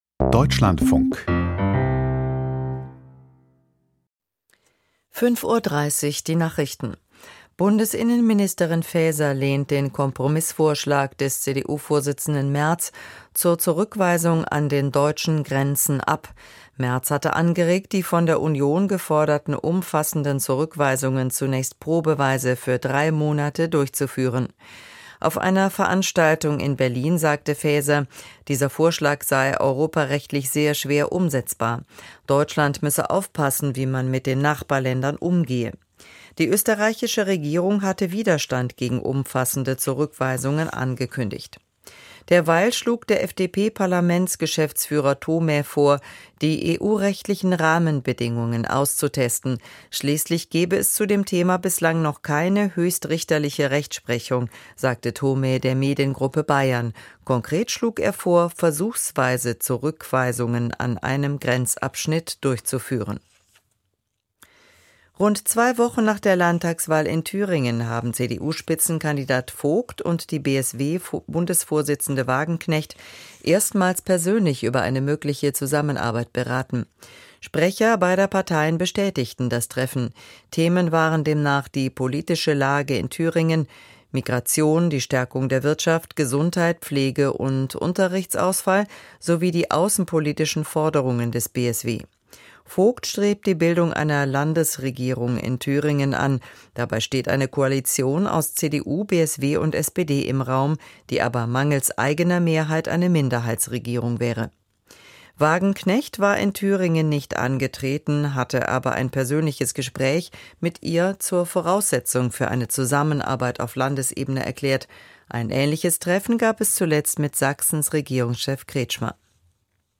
Krieg in der Ukraine: Deutschlands Haltung - Interview